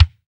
Index of /90_sSampleCDs/Roland L-CDX-01/KIK_Natural Kick/KIK_Natural K2
KIK ROCK D0F.wav